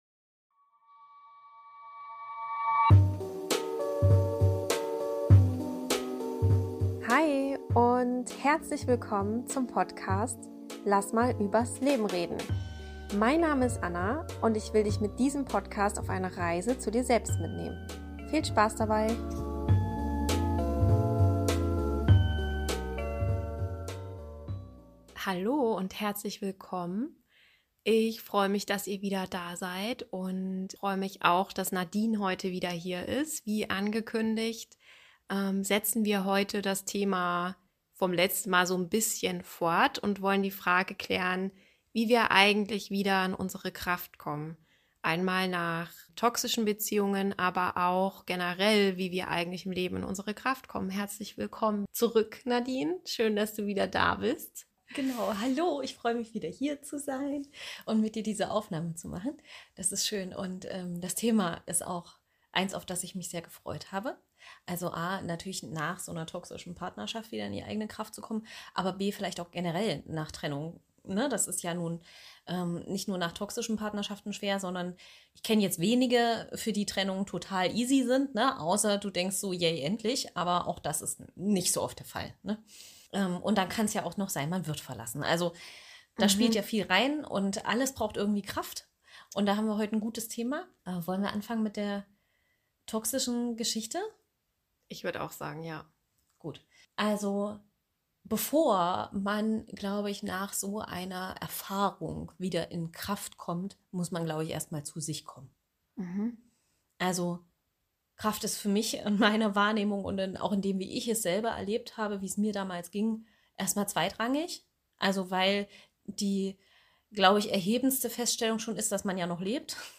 13: Hilfe nach Trennung: Wie komme ich wieder in meine Kraft? - Interview